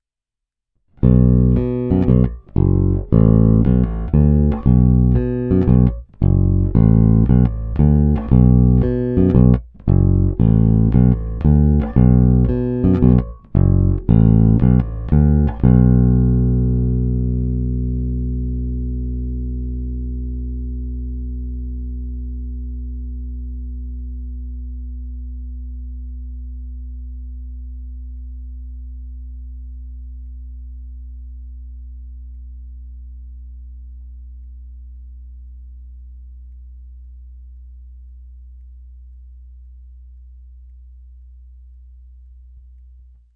Neskutečně pevný, zvonivý, s těmi správnými středy, co tmelí kapelní zvuk, ale při kterých se basa i prosadí.
Není-li uvedeno jinak, následující nahrávky jsou provedeny rovnou do zvukové karty, jen normalizovány, jinak ponechány bez úprav.
Hra u kobylky